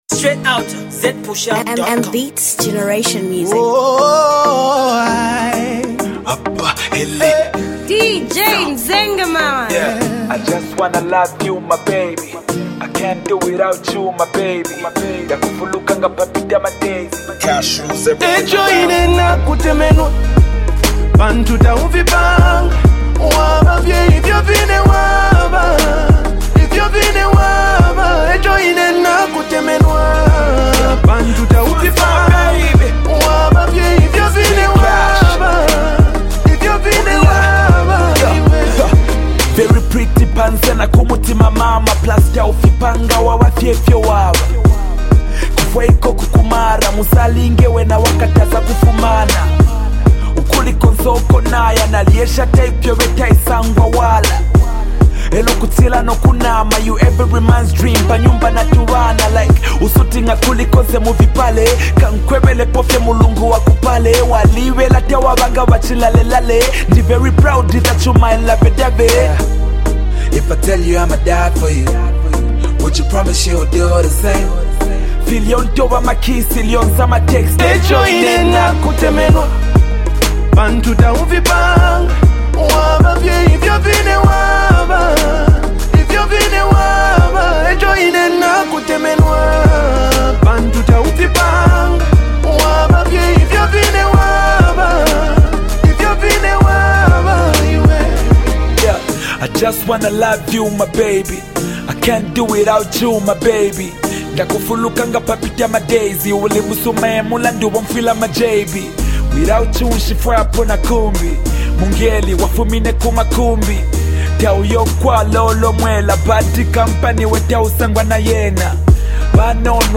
RnB joint